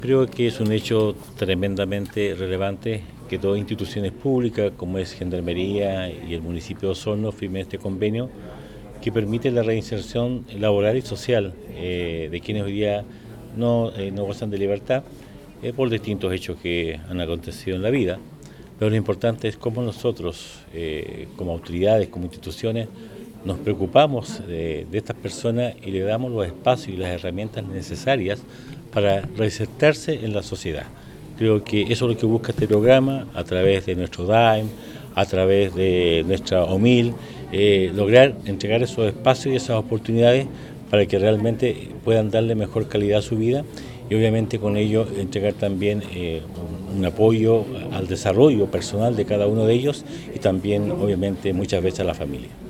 Por su parte, el Alcalde Emeterio Carrillo destacó las iniciativas que buscan aportar a la comunidad privada de libertad, entregándoles herramientas para reinsertarse en la sociedad otorgándole los espacios necesarios.
24-julio-24-emeterio-carrillo-convenio.mp3